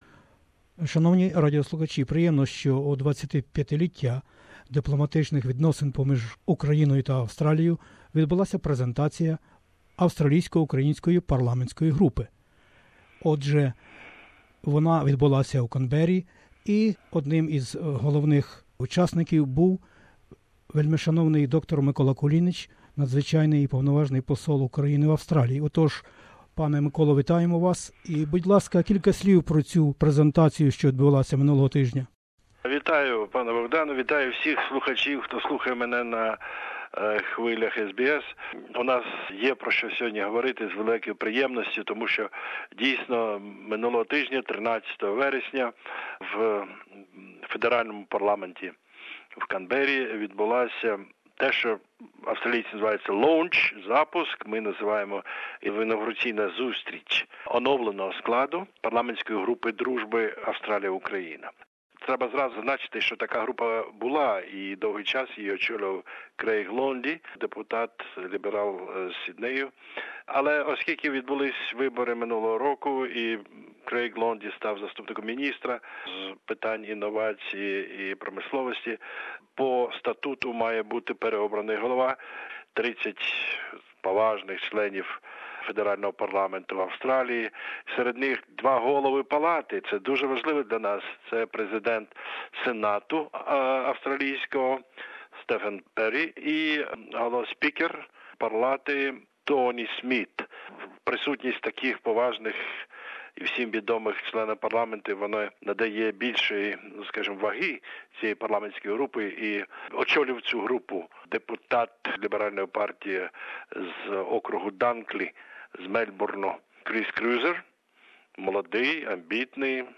interviewed His Excellency the Ambassador of Ukraine to Australia Dr Mykola Kulinich. We spoke about the reactivation of the Australia - Ukraine Parliamentary Friendship Group (AUPG) and much more…A friendship group between Australia and Ukraine has been formed in the Australian Federal Parliament of the 45th convocation.